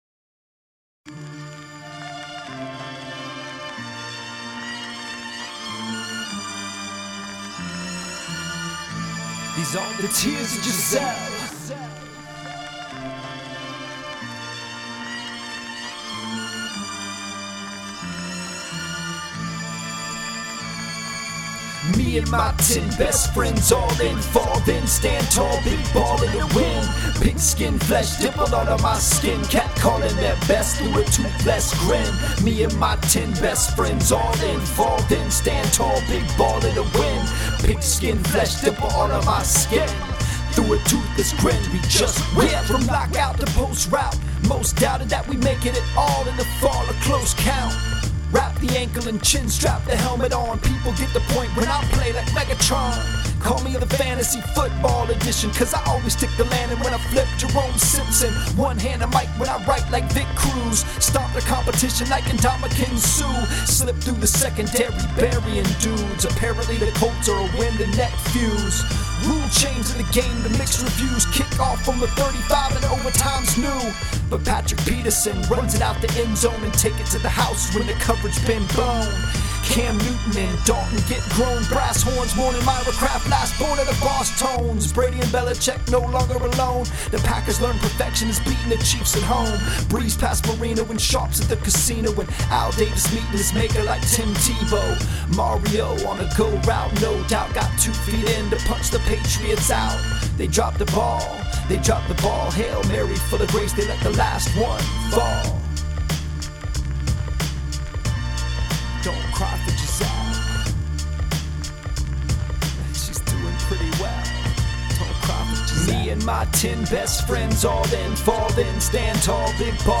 Either way, I was not up for spitting a hot 16 about football last night after the game.